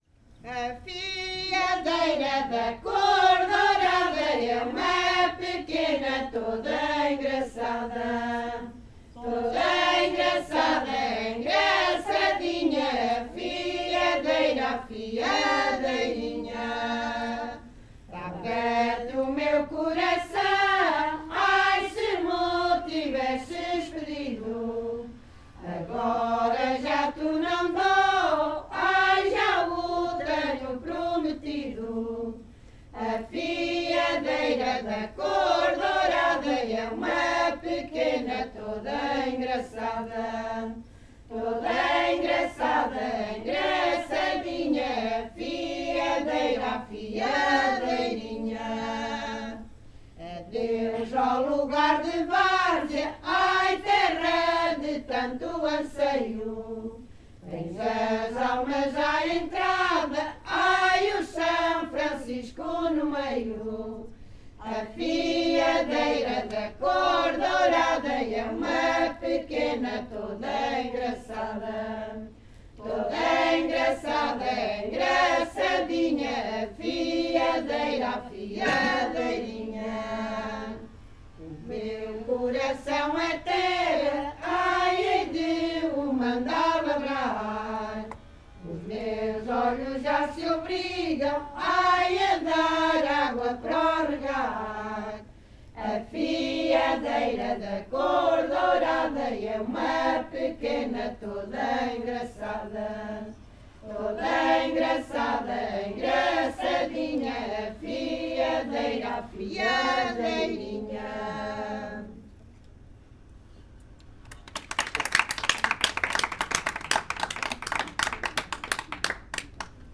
Grupo Etnográfico de Trajes e Cantares do Linho de Várzea de Calde durante o encontro SoCCos em Portugal - Duas chegam para fiar.